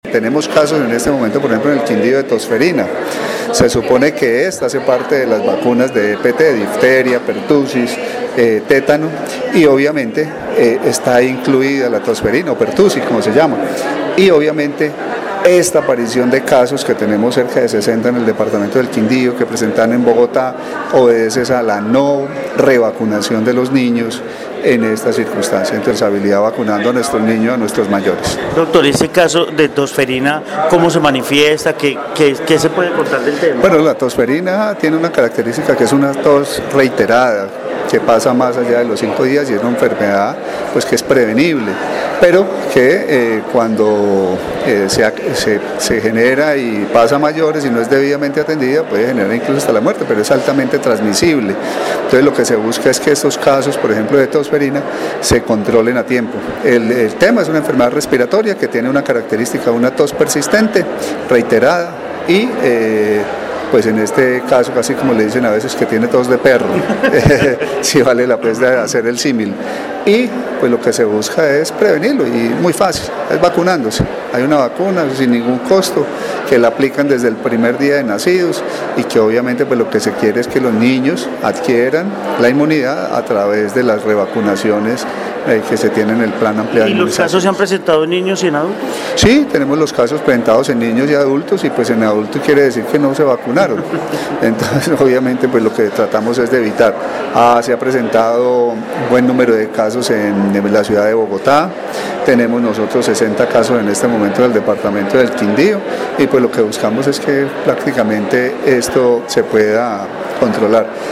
Carlos Alberto Gómez, secretario salud, Quindío